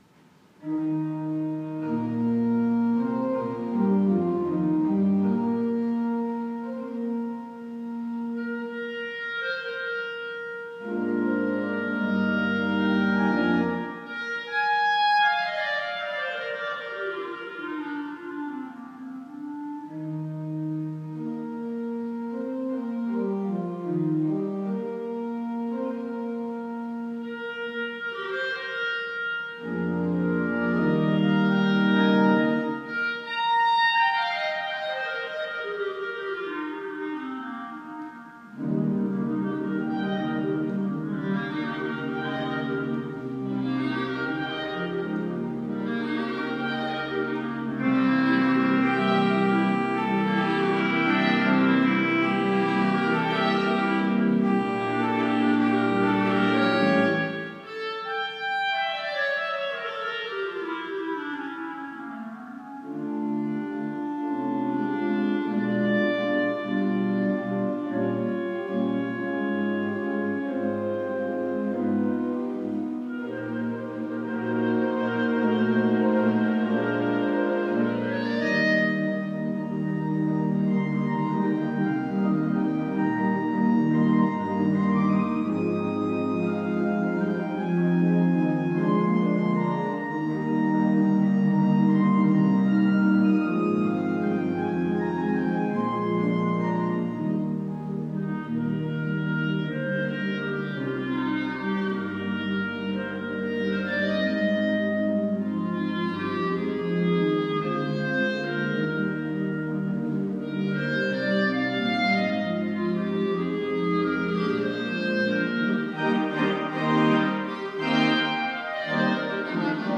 Classical (View more Classical Clarinet and Ensemble Music)
clarinets